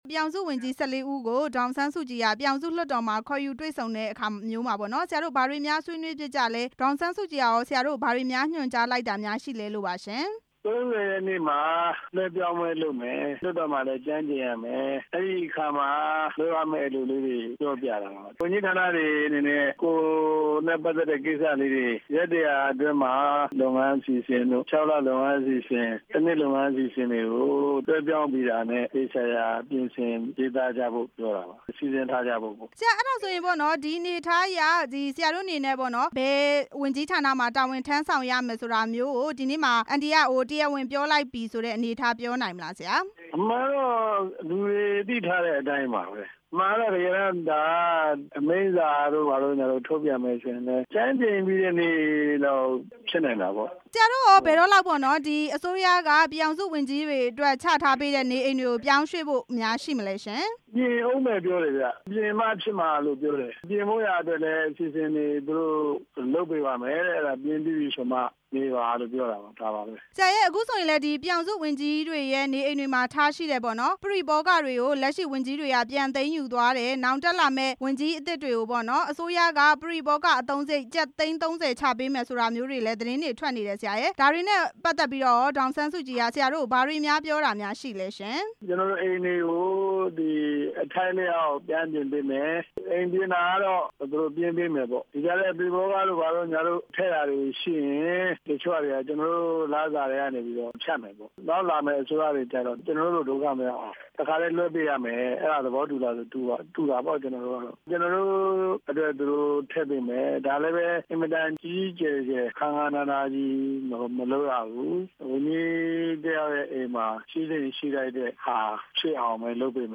ဝန်ကြီး ၁၄ ဦးနဲ့ ဒေါ်အောင်ဆန်းစုကြည် တွေ့ဆုံမှု ဦးသန့်စင်မောင်နဲ့ မေးမြန်းချက်